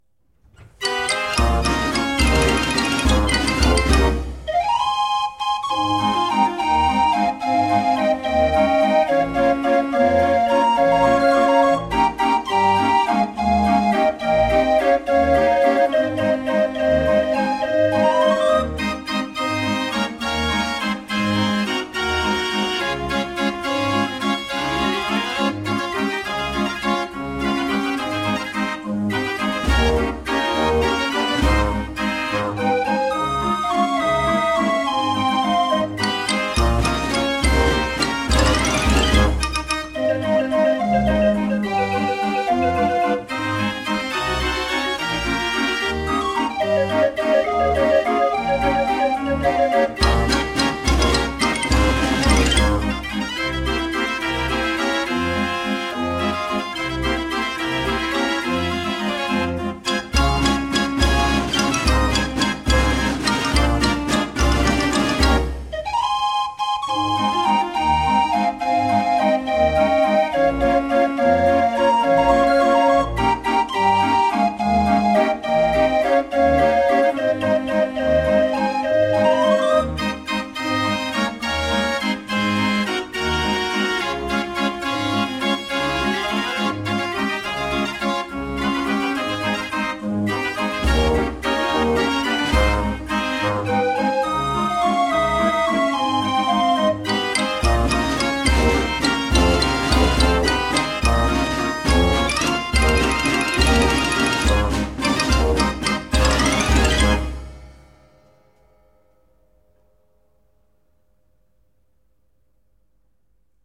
Historisches Drehorgeltreffen in Lichtensteig (SG)
Holländische Strassenorgel
(eine schwebend gestimmt)
Xylophon
2 Kastagnetten